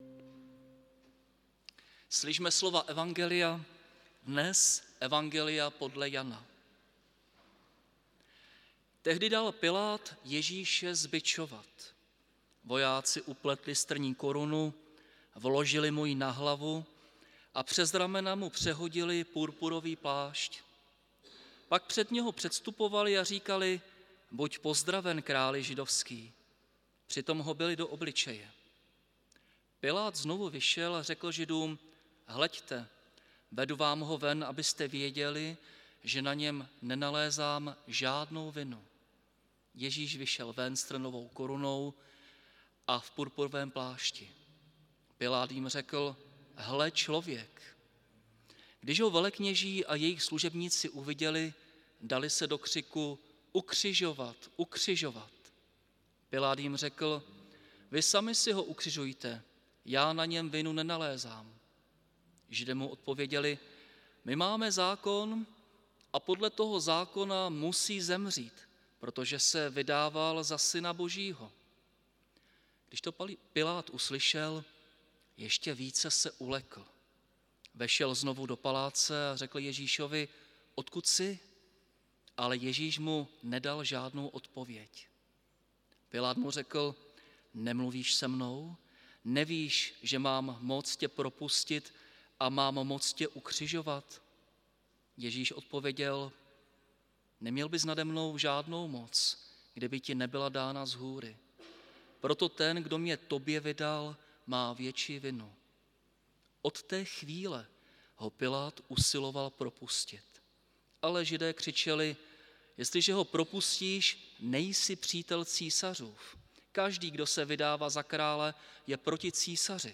Velkopáteční bohoslužba se slavením sv. Večeře Páně 29. března 2024
Bohoslužbu vedl a kázáním na text z evangelia Janova sloužil